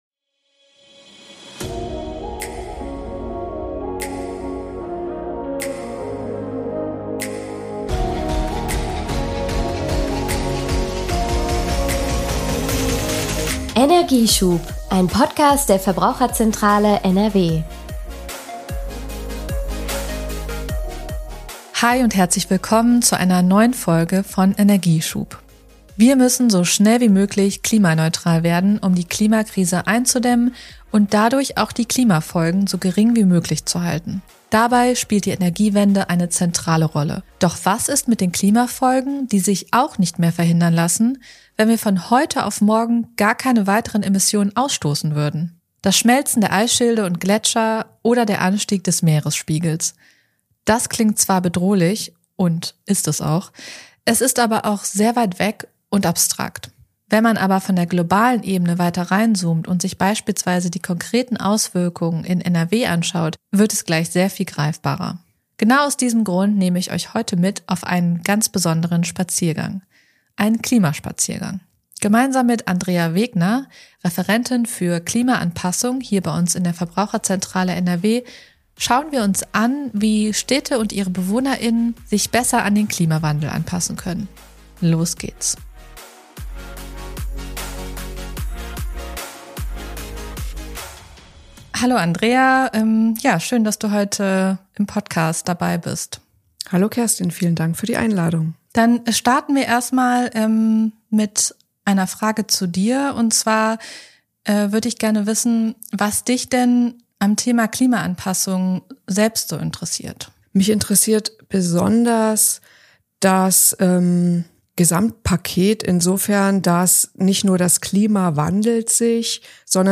Beschreibung vor 1 Jahr Kommt mit auf einen spannenden Klimaspaziergang durch die Stadt!